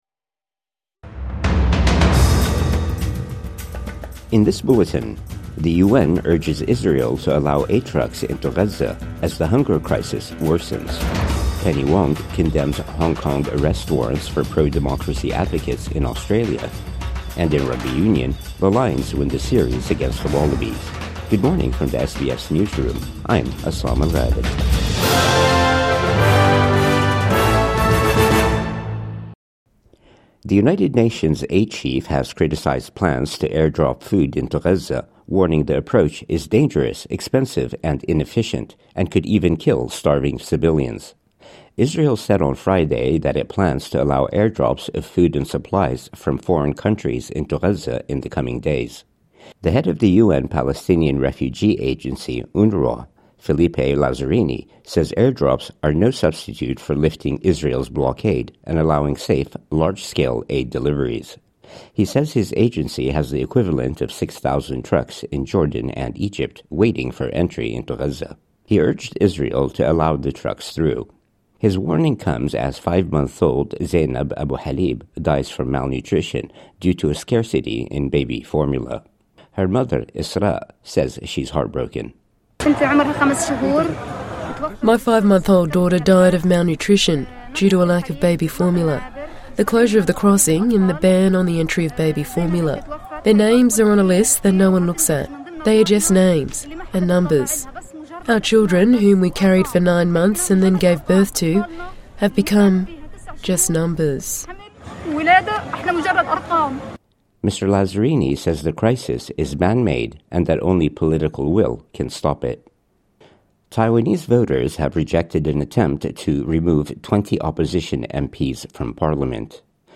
Listen to Australian and world news, and follow trending topics with SBS News Podcasts.